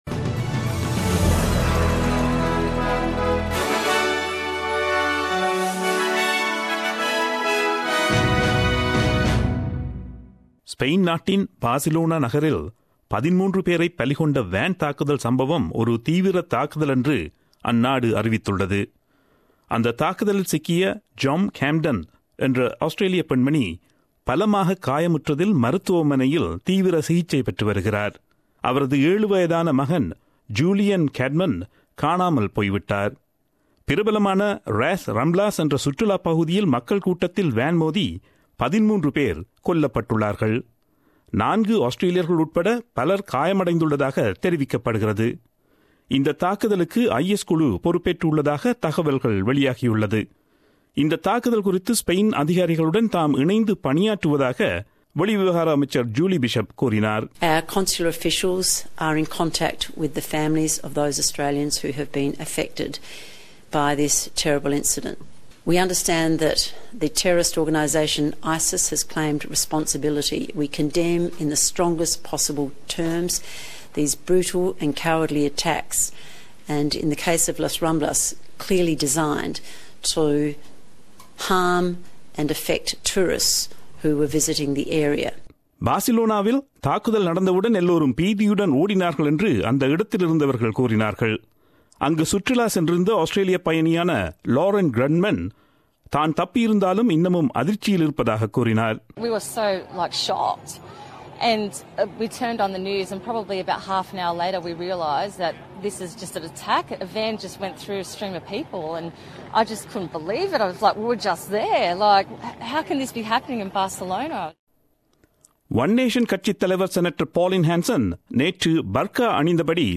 Australian news bulletin aired on Friday 18 August 2017 at 8pm.